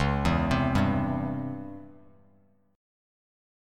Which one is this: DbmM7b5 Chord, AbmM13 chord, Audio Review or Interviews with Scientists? DbmM7b5 Chord